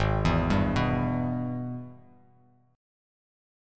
G#mM7#5 chord